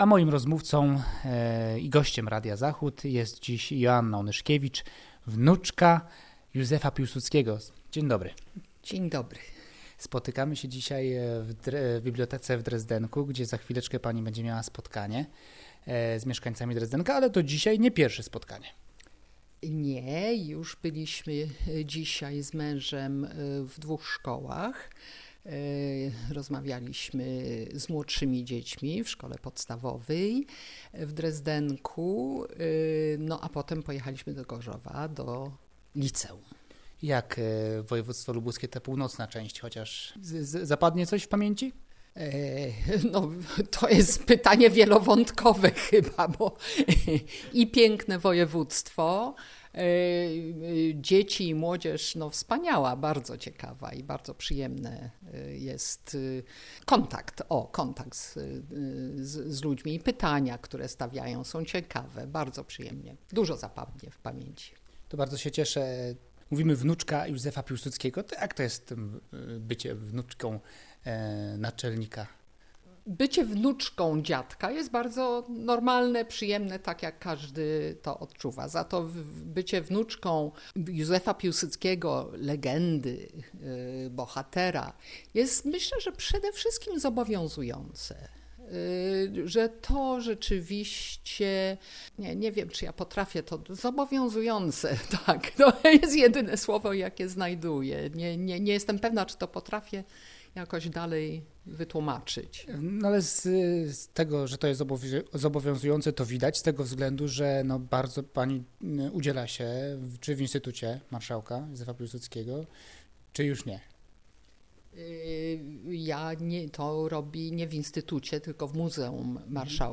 Rozmowa z wnuczką Józefa Piłsudskiego